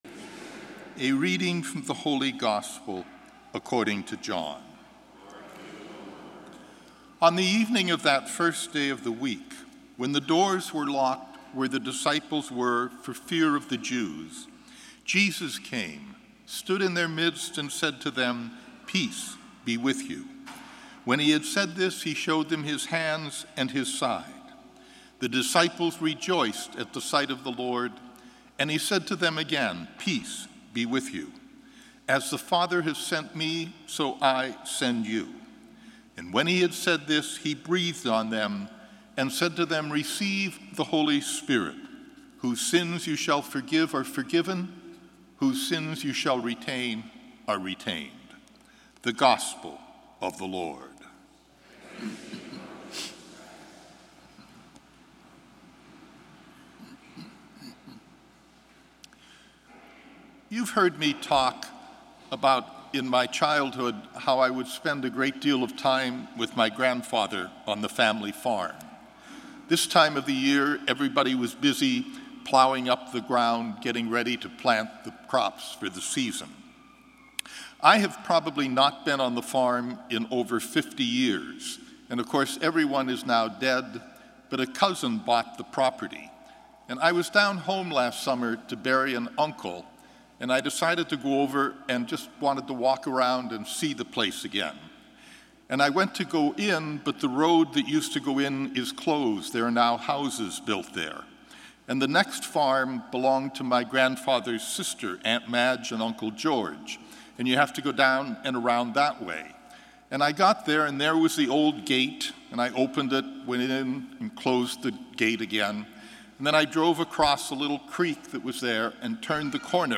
Gospel & Homily May 15, 2016